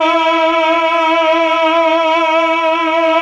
RED.CHOR1 22.wav